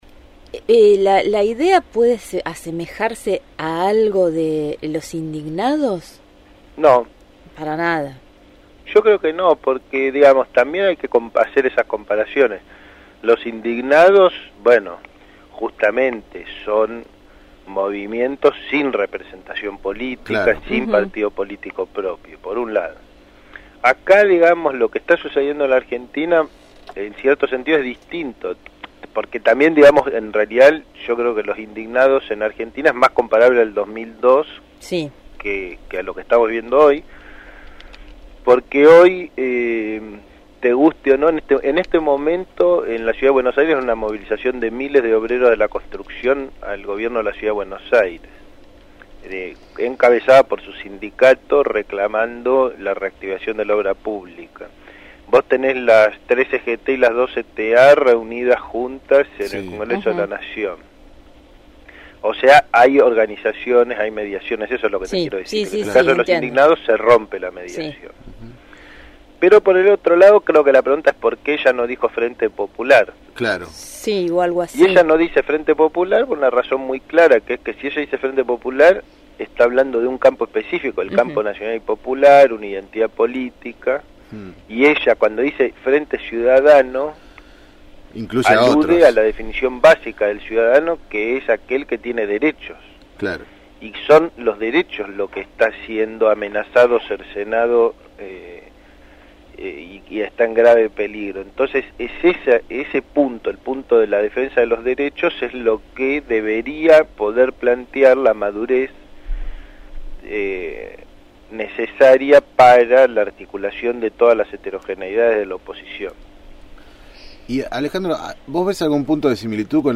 Alejandro Grimson, doctor en Antropología por la Universidad de Brasilia y autor del libro «Mitomanías argentinas», dialogó con